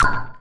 描述：This is part of a sound kit i've done in 2002 during a session testing Deconstructor from Tobybear, the basic versionwas a simple drumloop, sliced and processed with pitchshifting, panning, tremolo, delay, reverb, vocoder.. and all those cool onboard fxTweaking here and there the original sound was completely mangled..i saved the work in 2 folders: 'deconstructionset' contain the longer slices (meant to be used with a sampler), 'deconstructionkit' collects the smallest slices (to be used in a drum machine)
标签： cuts digital drumkit fx glitch noise slices soundeffect
声道立体声